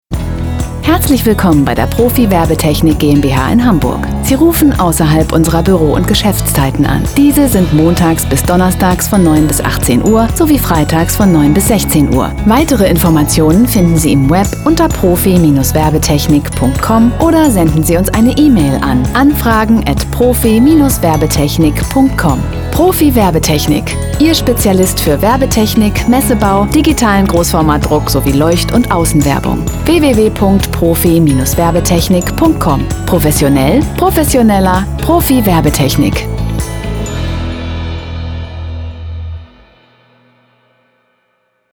Commercieel, Toegankelijk, Warm, Zacht, Zakelijk
Telefonie